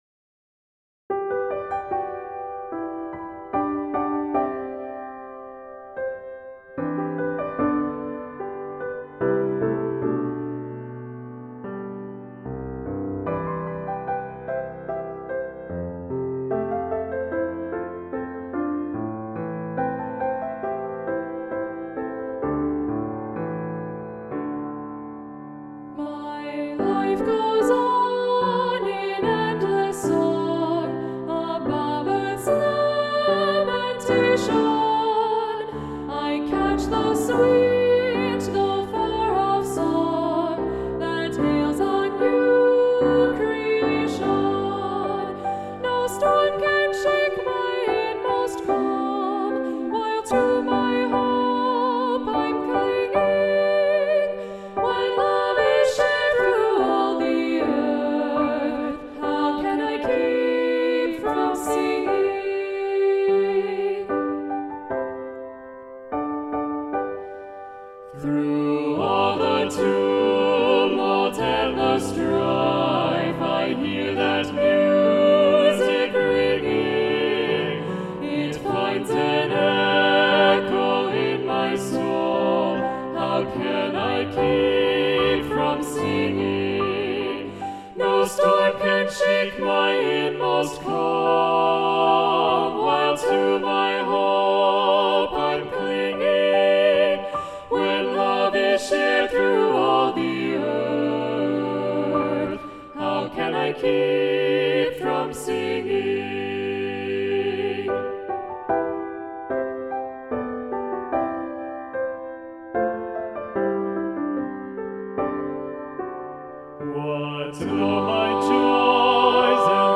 Choral Music
(arrangement of the folk-like song)